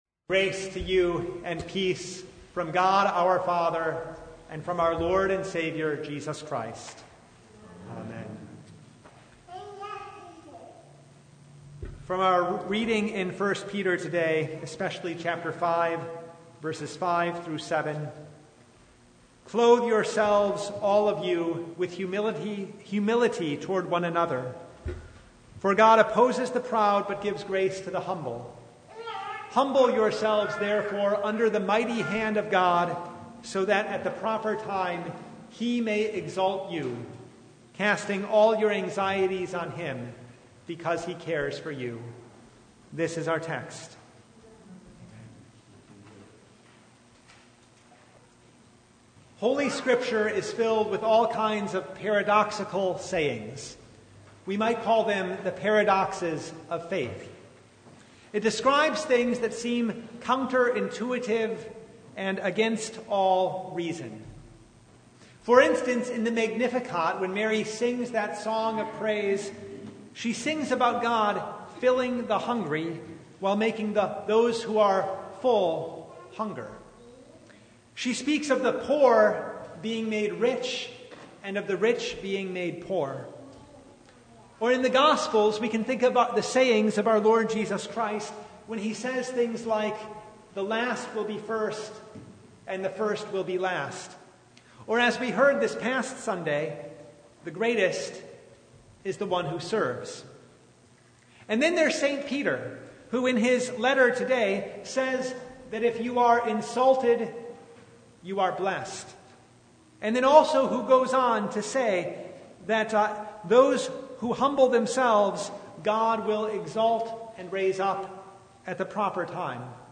Service Type: Lent Midweek Noon